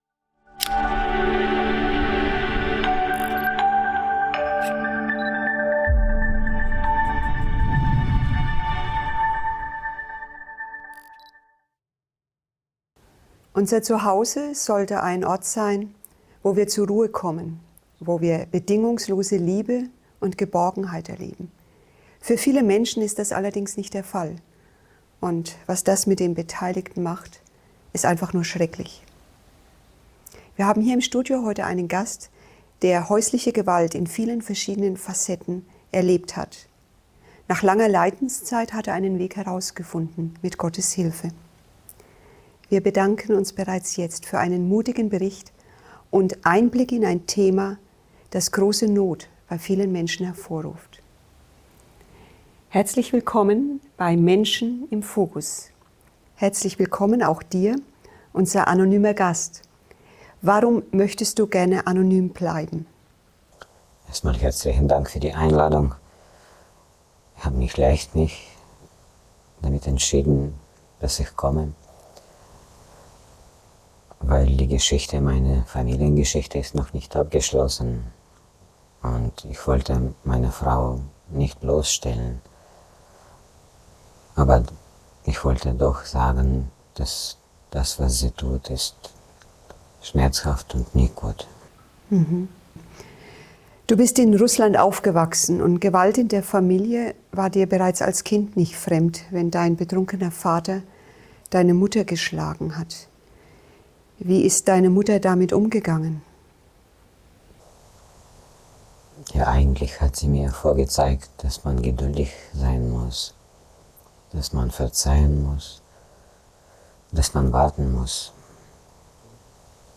In diesem bewegenden Vortrag schildert ein anonymer Gast seine Erfahrungen mit häuslicher Gewalt und emotionalem Missbrauch. Durch Gebet und den Glauben fand er schließlich den Mut, sich von der Beziehung zu befreien.